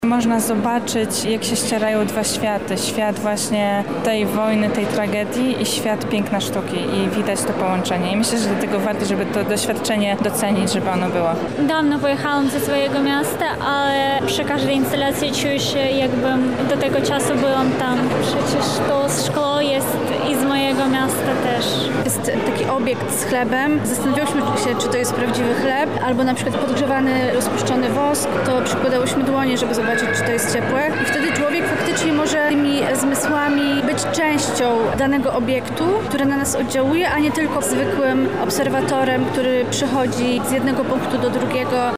Zapytaliśmy zwiedzających, jakie są ich odczucia po obejrzeniu wystawy:
sonda